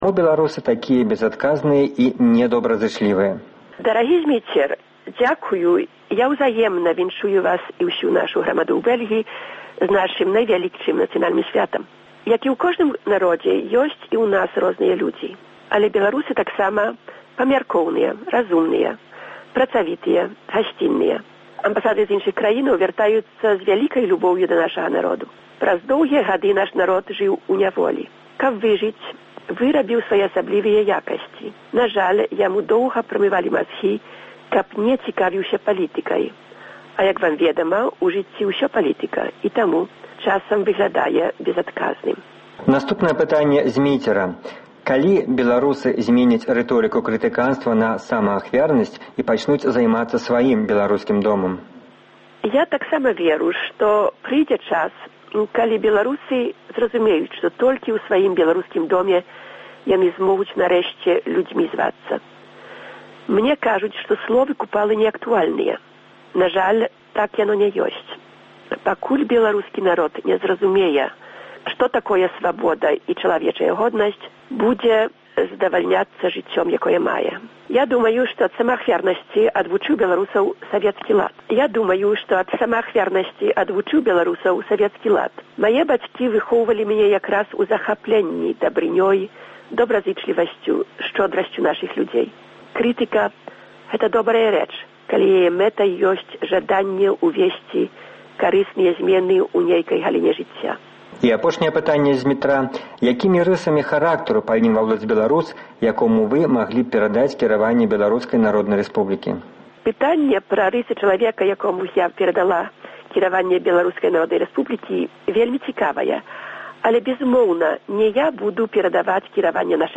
Онлайн-канфэрэнцыя
Онлайн-канфэрэнцыя з старшынёй Рады БНР Iвонкай Сурвілай.